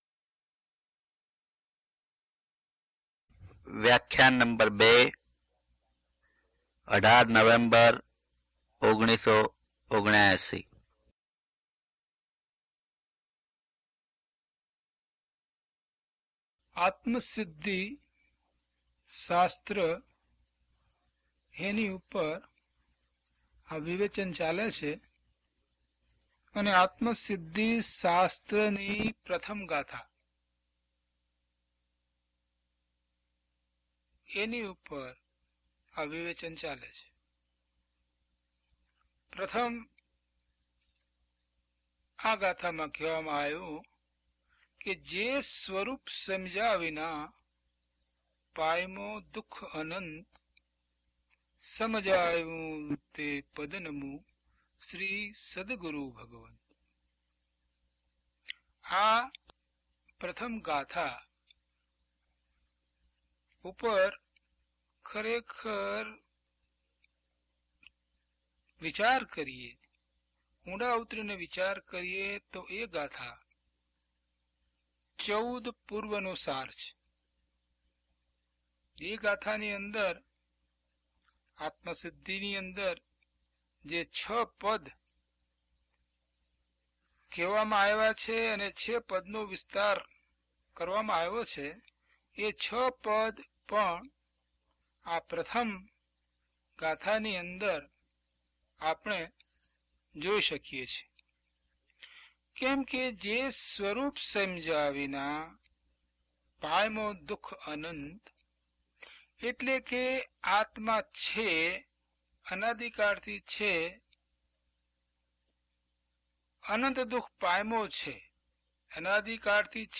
DHP011 Atmasiddhi Vivechan 2 - Pravachan.mp3